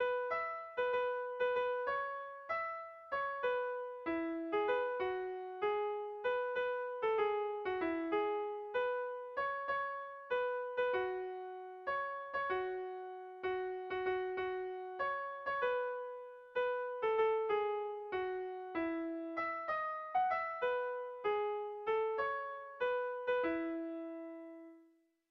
Gabonetakoa
Zortziko txikia (hg) / Lau puntuko txikia (ip)
ABDE